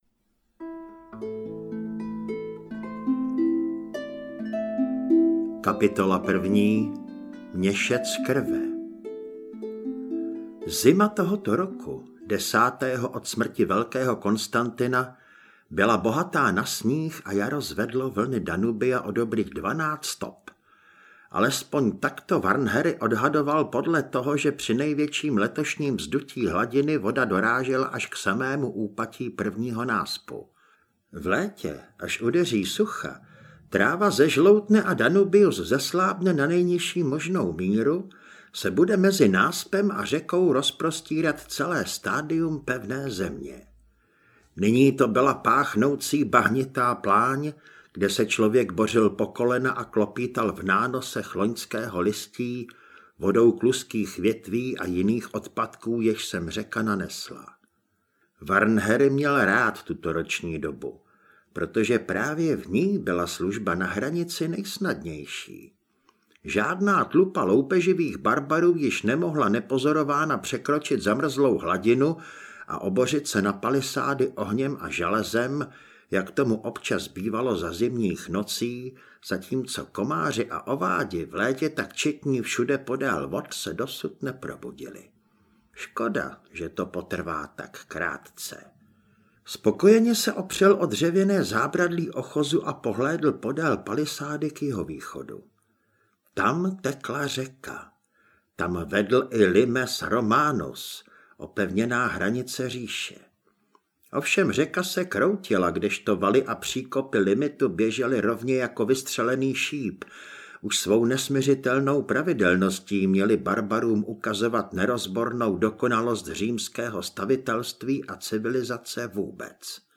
Stín modrého býka audiokniha
Ukázka z knihy
stin-modreho-byka-audiokniha